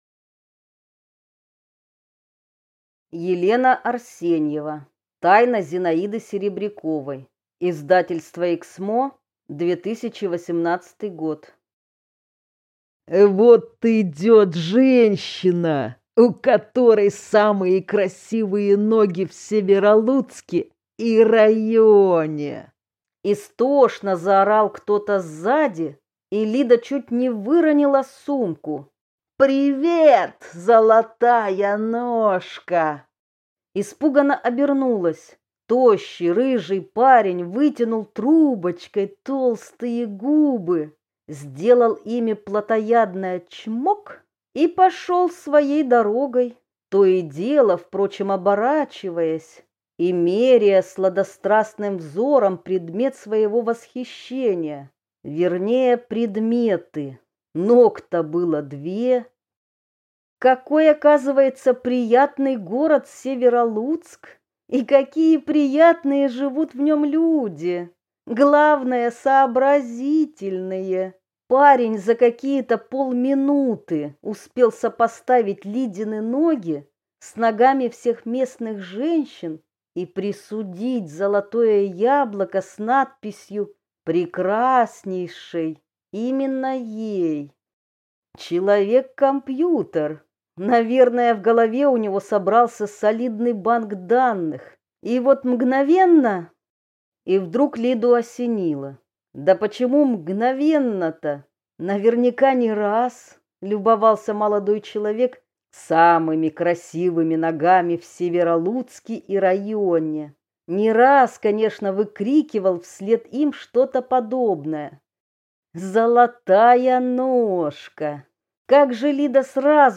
Аудиокнига Тайна Зинаиды Серебряковой | Библиотека аудиокниг